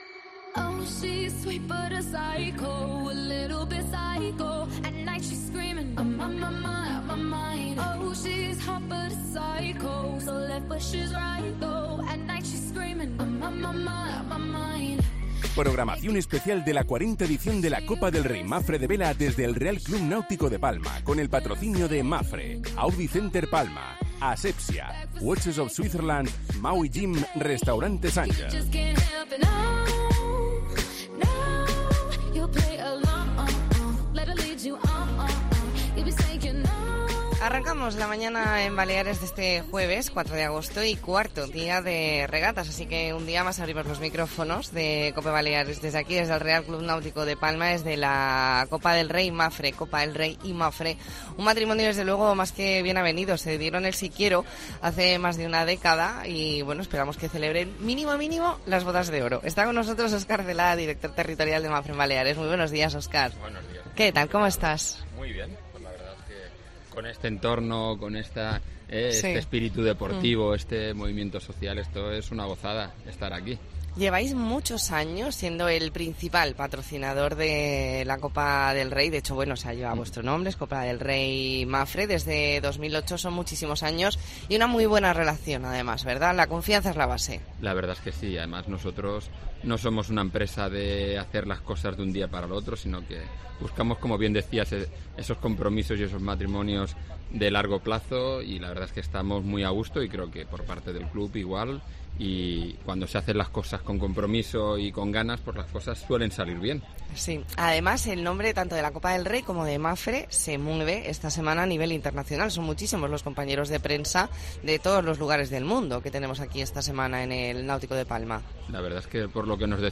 AUDIO: Especial La Mañana en COPE Más Mallorca desde el RCNP con motivo de la 40 Copa del Rey Mapfre